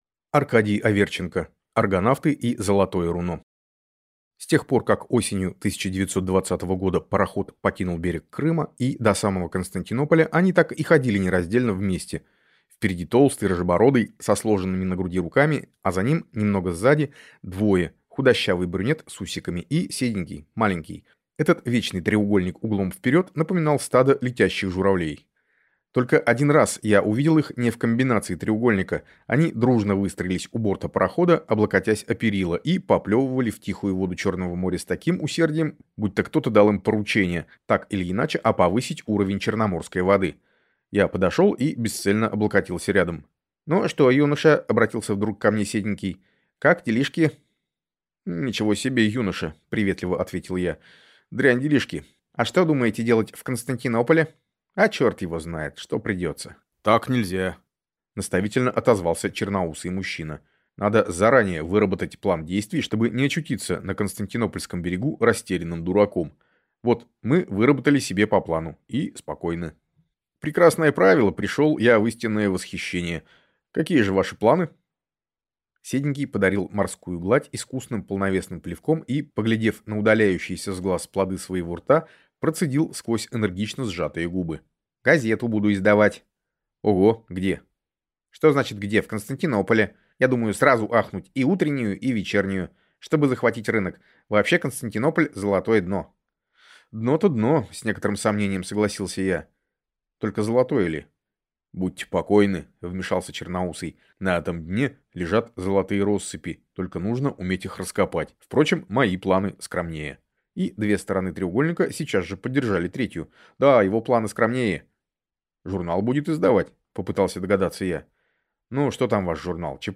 Аудиокнига Аргонавты и золотое руно | Библиотека аудиокниг